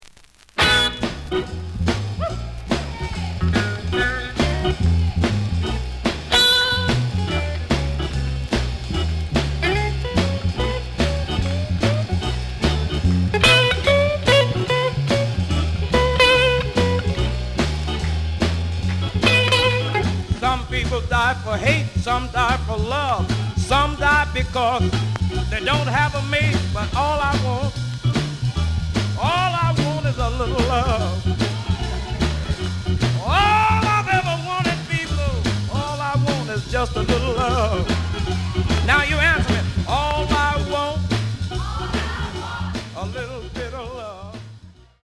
The audio sample is recorded from the actual item.
●Genre: Blues
Edge warp.